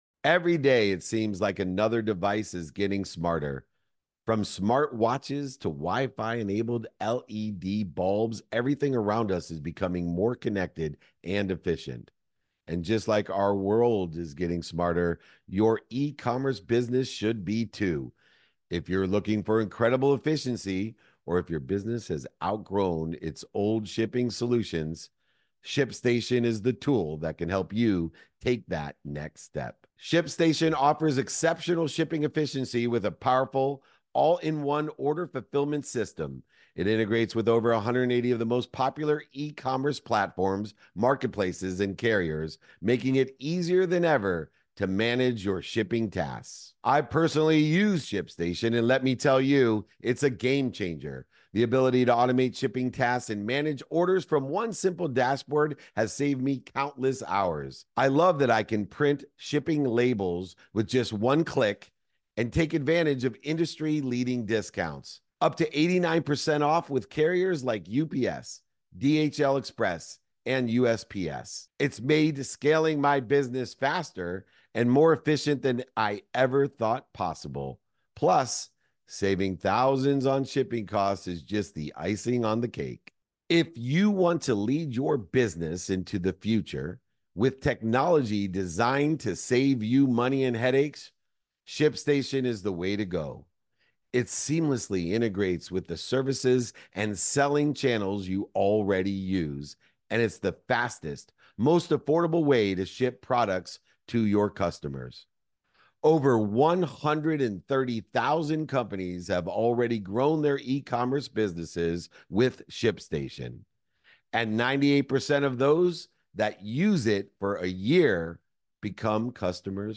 In today’s episode, I’m joined by John Assaraf, a behavioral neuroscience expert and high-performance coach.